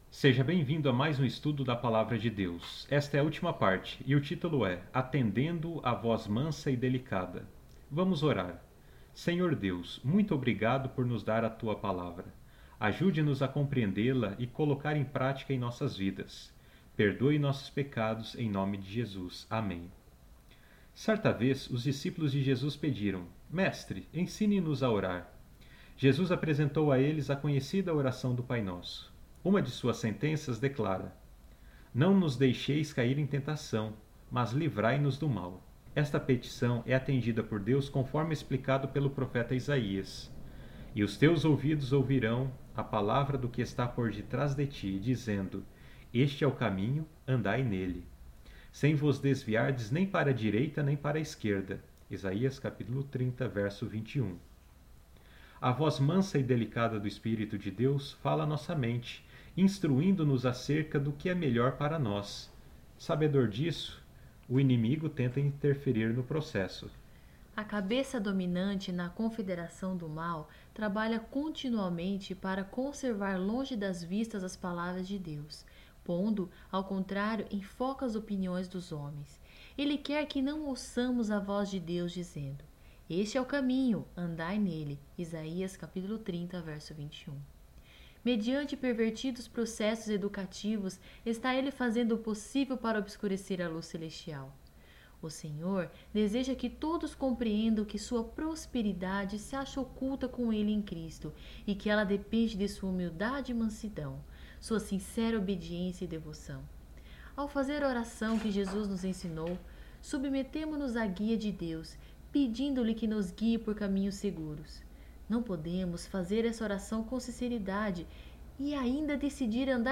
Áudios - Lição em Áudio